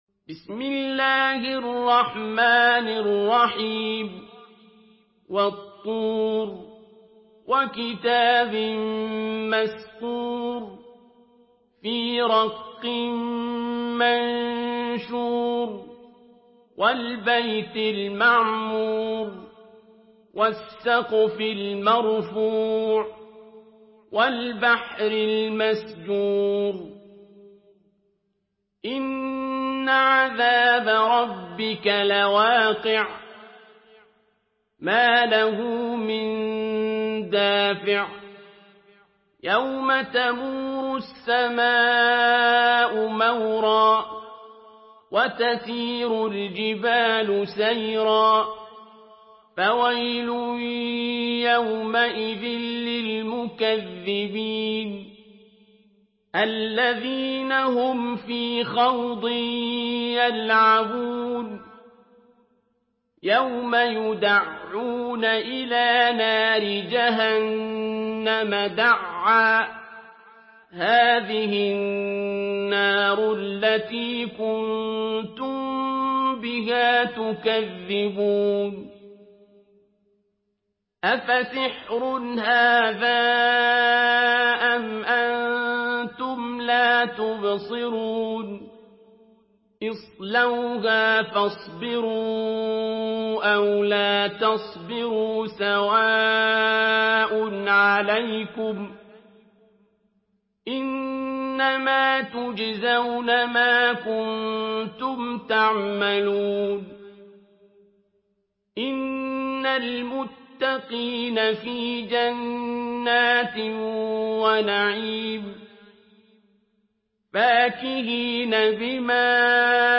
تحميل سورة الطور بصوت عبد الباسط عبد الصمد
مرتل